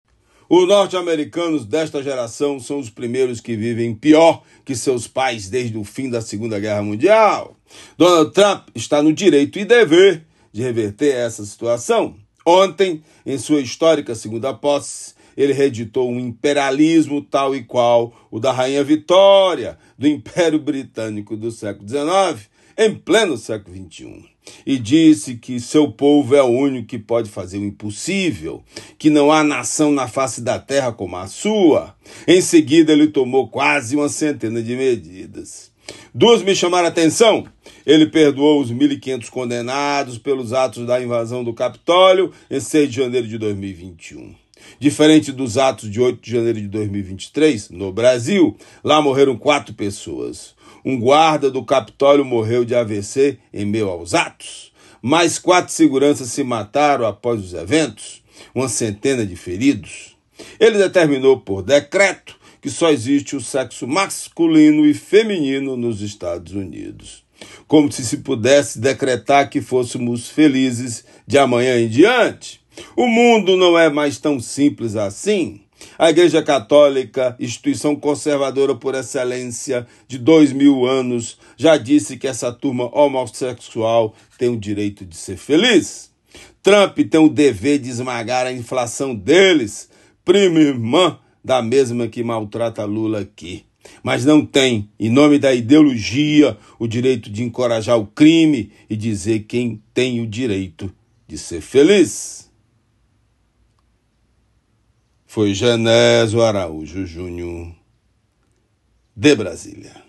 Comentário do jornalista
direto de Brasília.